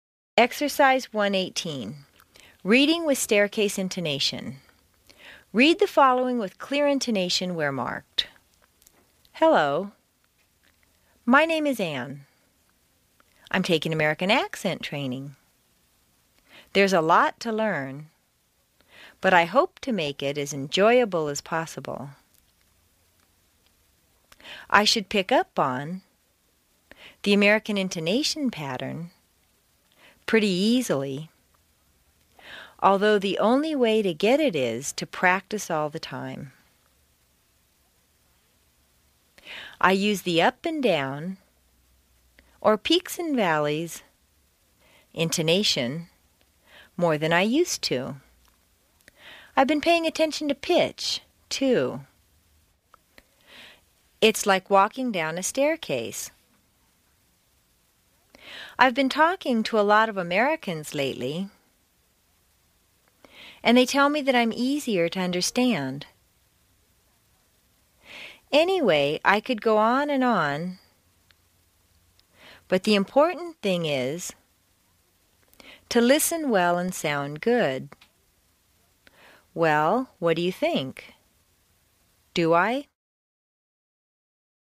美式英语正音训练第20期:Exercise 1-18 Reading with Staircase Intonation 听力文件下载—在线英语听力室
在线英语听力室美式英语正音训练第20期:Exercise 1-18 Reading with Staircase Intonation的听力文件下载,详细解析美式语音语调，讲解美式发音的阶梯性语调训练方法，全方位了解美式发音的技巧与方法，练就一口纯正的美式发音！